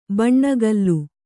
♪ baṇṇagallu